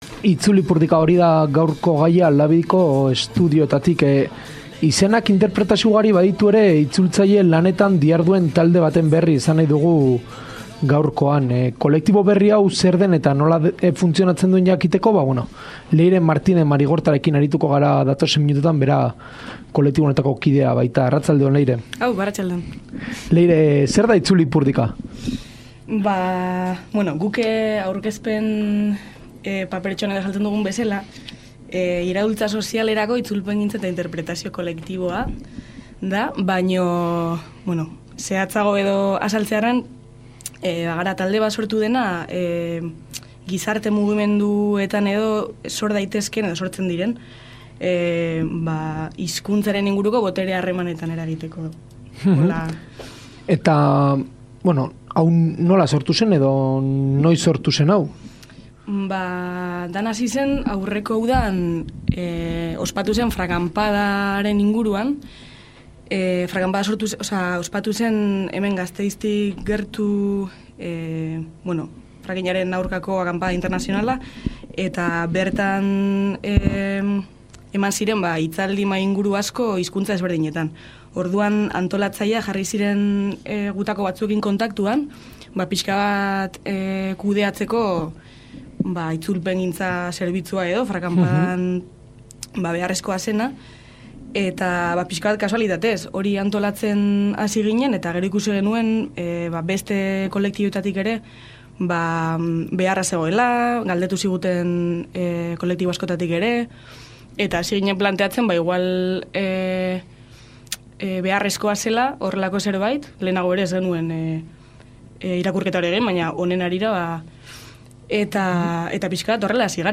Itzulpengintzaz eta interpretazioaz hausnartu dugu gaurko elkarrizketaren tartean, edo jardun hori helburu jakinetarako baliatzeaz, akaso. Izan ere, hainbat itzultzailek Itzulipurdika kolektiboa sortu dute, herri mugimenduko eragileei itzulpengintza zerbitzuak eskaintzeko.